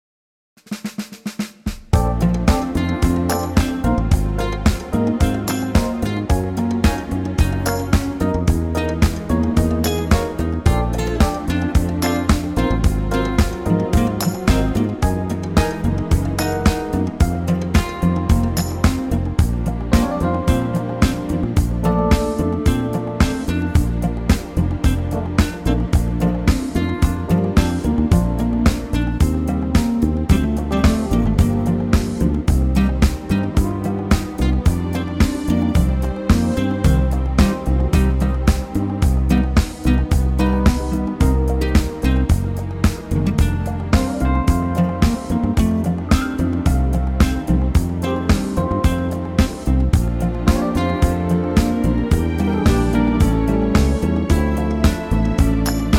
ALL BRASS REMOVED!
key - Bb - vocal range - A to C
Here's a Rhumba tempo but with an 80's vibe going on!